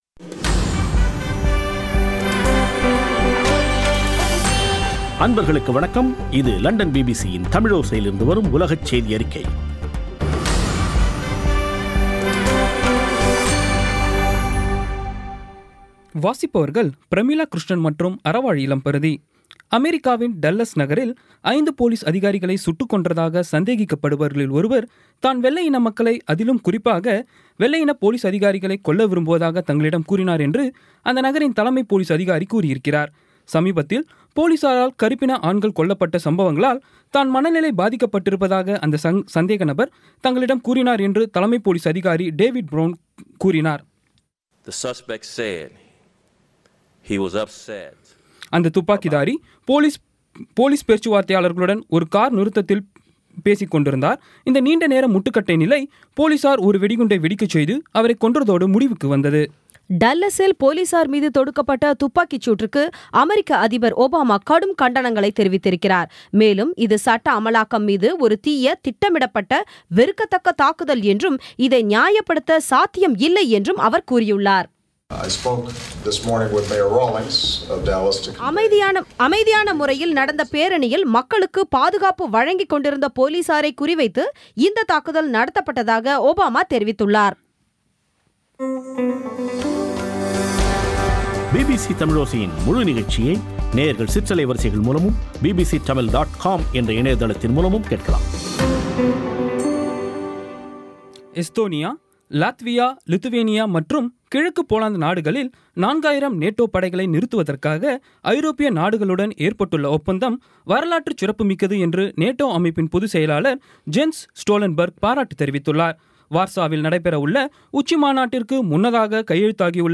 பிபிசி தமிழோசை செய்தியறிக்கை (08.07.2016)